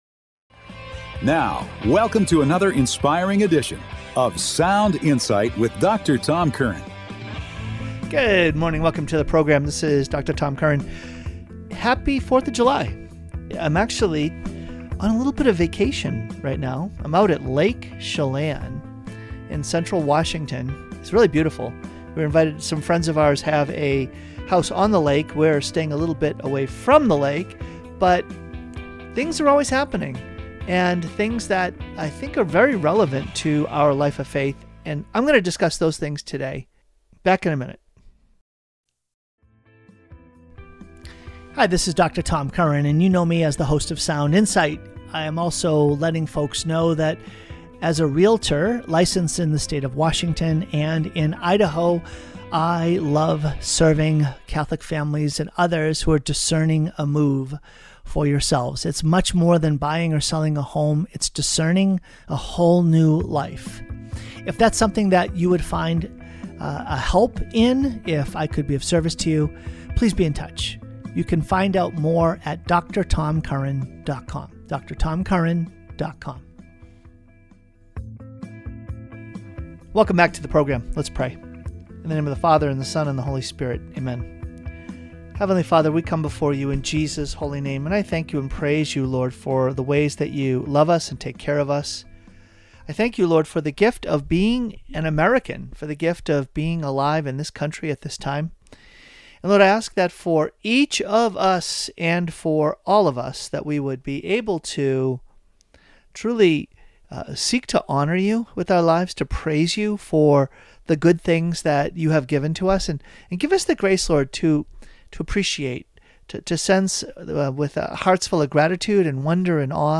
Open Forum